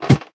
ladder4.ogg